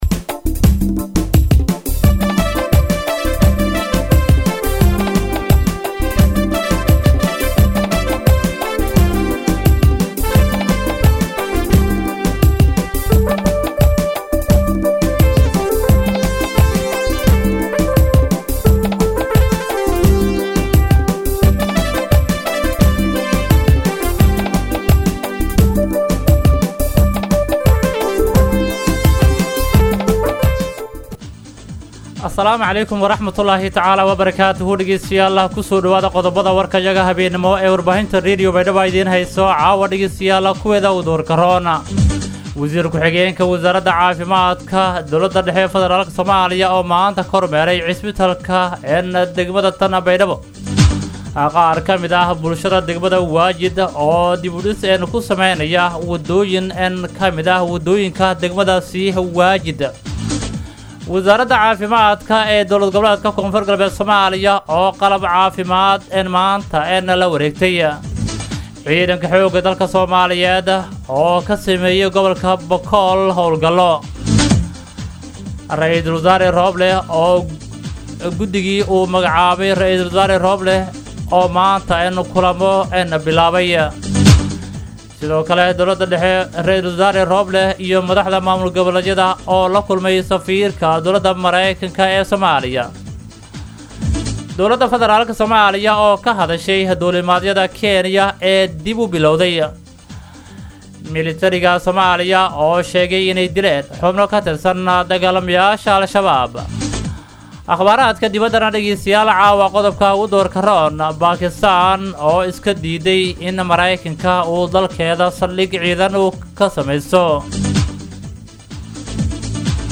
BAYDHABO–BMC:–Dhageystayaasha Radio Baidoa ee ku xiran Website-ka Idaacada Waxaan halkaan ugu soo gudbineynaa Warka caawa ee ka baxay Radio Baidoa.